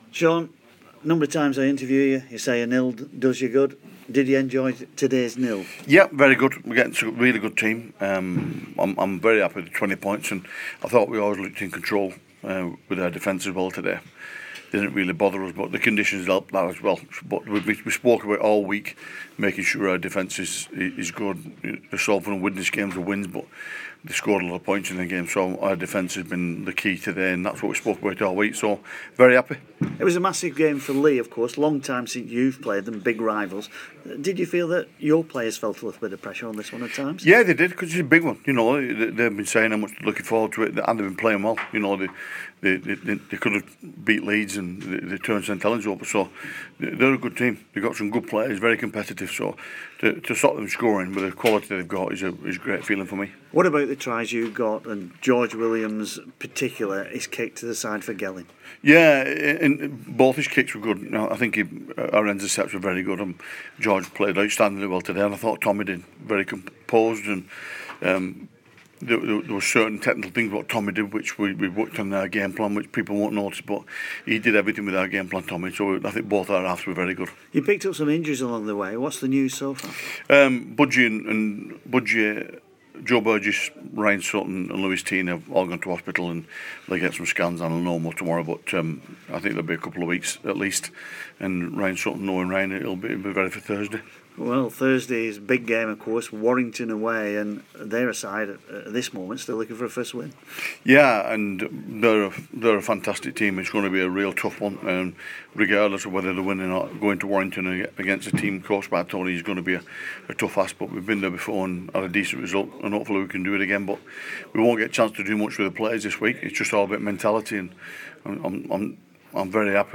Wigan Warriors coach Shaun Wane shares his thoughts following a 22-0 win over local rivals Leigh Centurions.